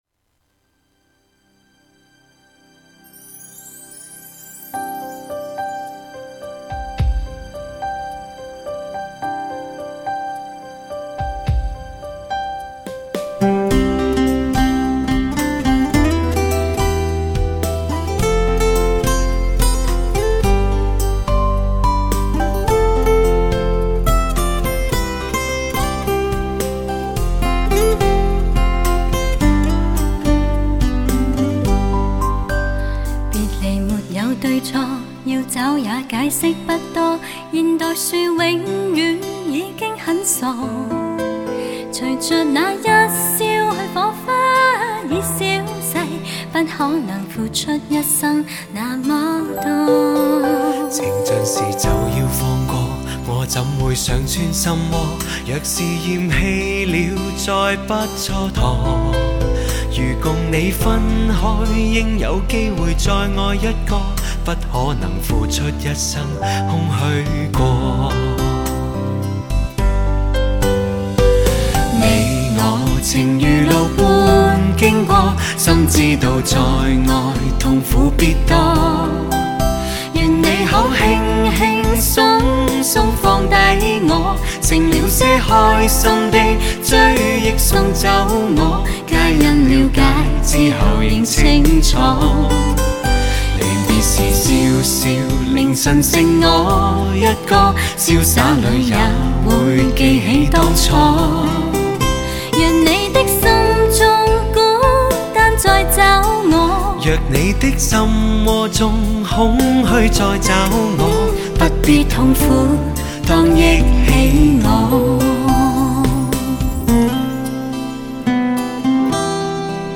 聆听古典儒雅与甜美可人的音乐对话，
品味最时尚浪漫都市情歌，优雅温婉、声音通透、高度传真、无可抗拒。